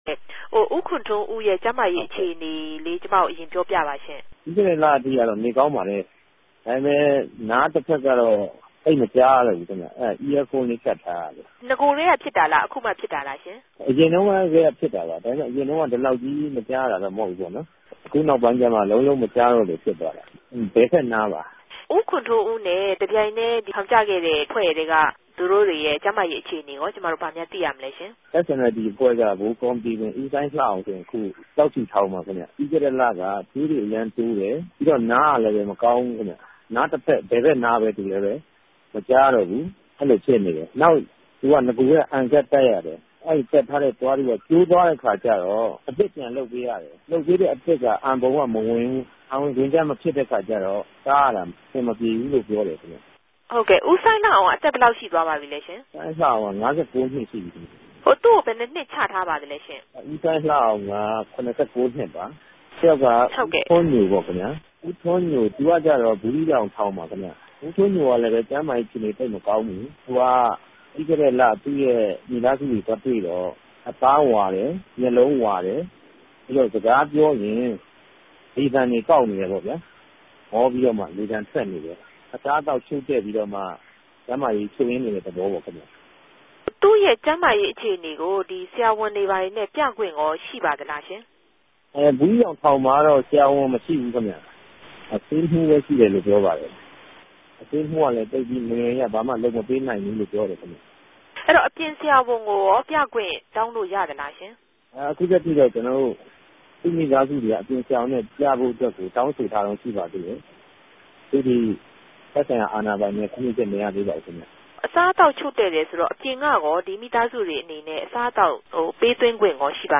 ဆက်သြယ်မေးူမန်းထားတာကိုလည်း နားထောငိံိုင်ပၝတယ်။